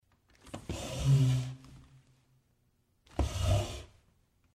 Звуки стульев
Двигают стул